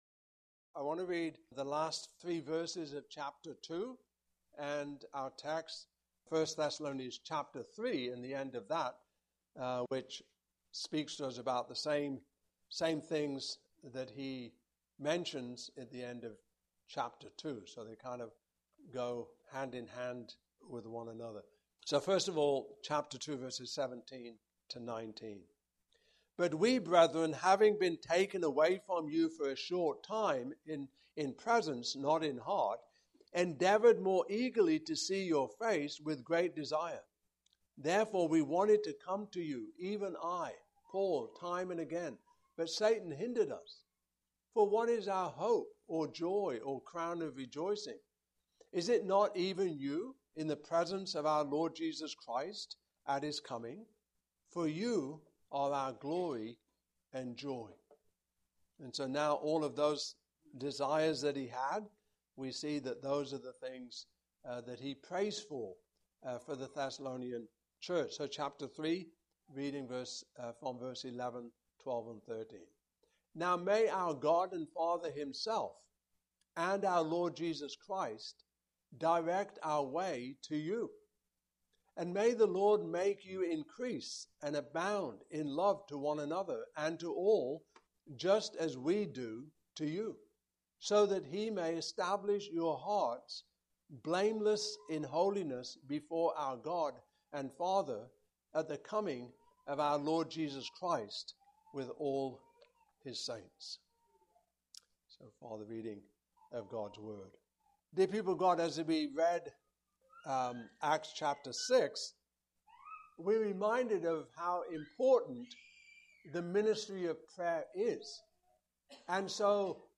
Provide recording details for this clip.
I Thessalonians 2024 Passage: I Thessalonians 2:17-20; 3:11-13 Service Type: Morning Service Topics